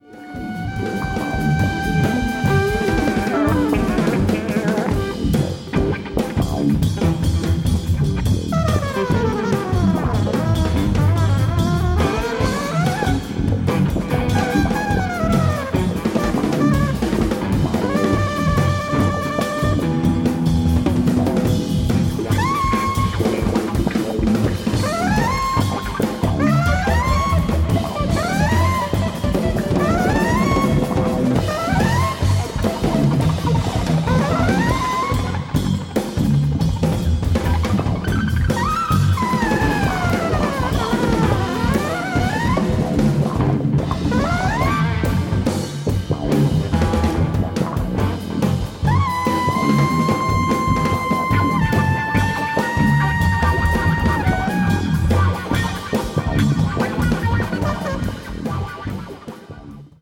die Trompete gewesen